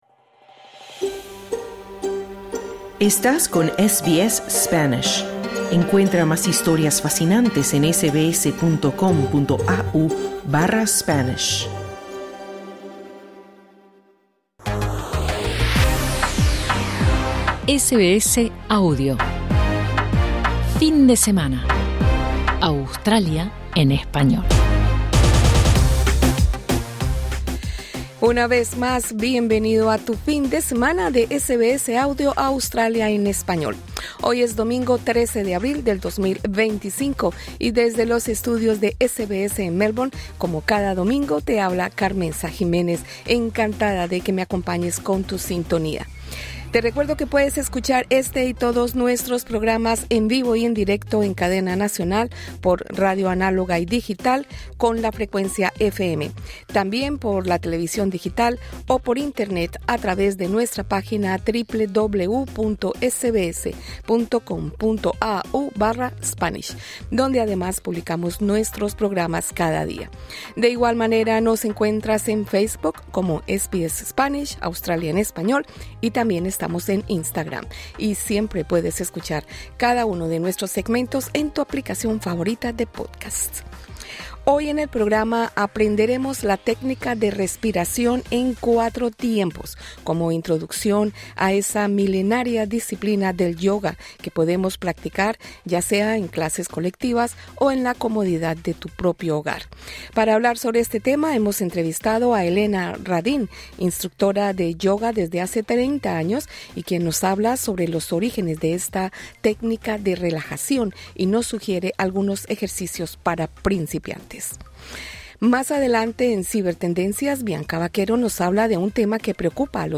Hoy en el programa hablamos de la técnica de respiración en 4 tiempos, como introducción a la milenaria disciplina del Yoga. Entrevista